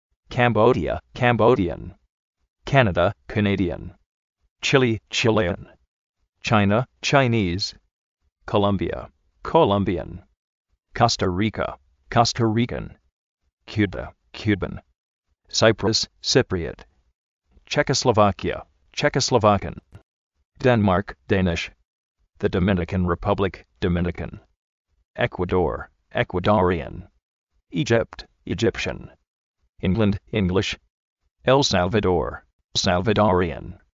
afgánistan, áfgan
alyíria, alyírian
andórra, andórran
aryentína, aryentínian, aryentín
ostréilia, austréilian
bélyium, bélyian
bresíl, bresílian
bulgária, bulgárian